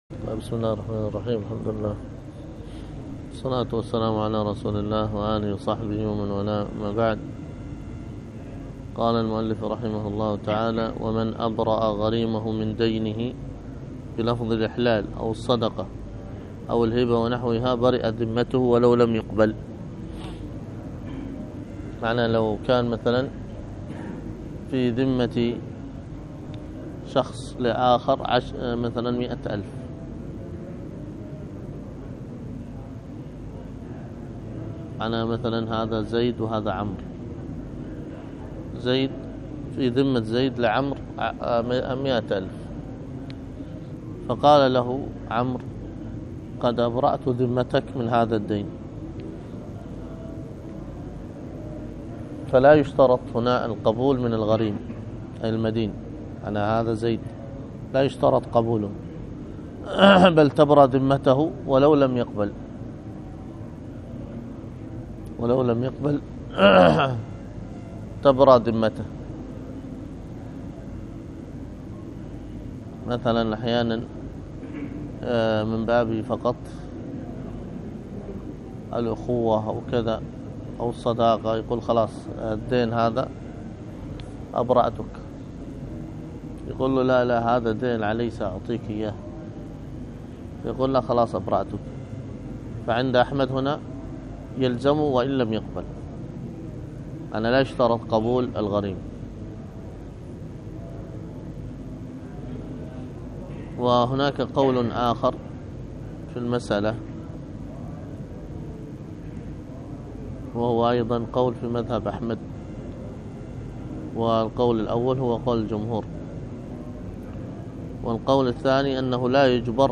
الدرس في كتاب الوقف 7